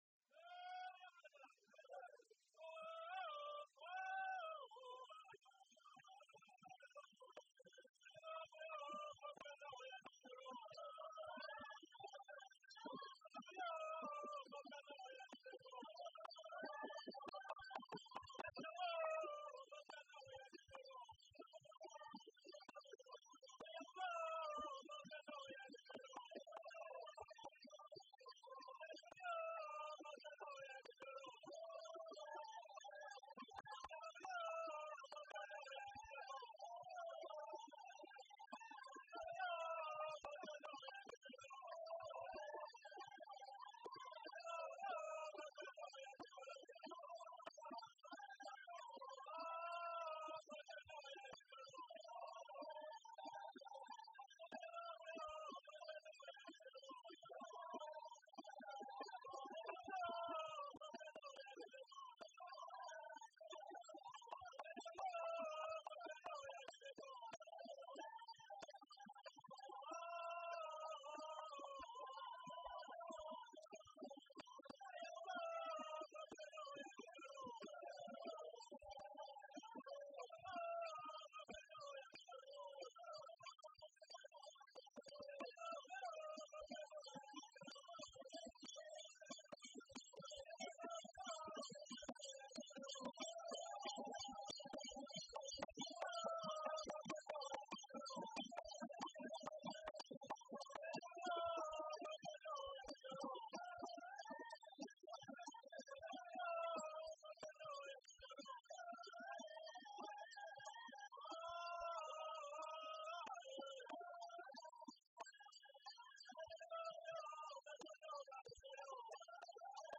Zande men and women
Folk songs, Zande
Field recordings
sound recording-musical
Two short lengths of stick are hung inside the shell and the result is similar in shape and size to cow bells used in many countries of the world.
Zande dance with Bugu slit drum, 3 Ndimu double headed, conical cross laced drums, metal hand bells, and Akiri bells made of the borassus palm with wooden clappers.